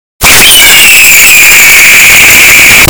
Loudest Sound Ever Sound Button - Free Download & Play